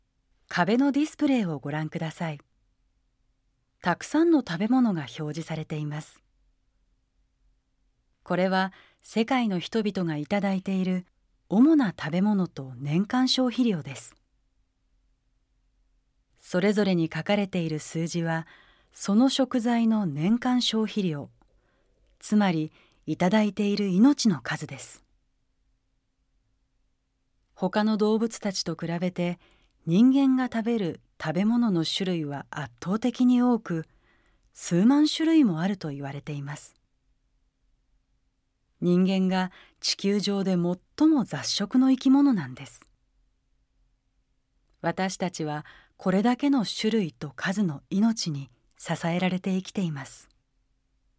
参考： 国連食糧農業機関（FAO） など 音声ガイドナレーター：宇賀なつみ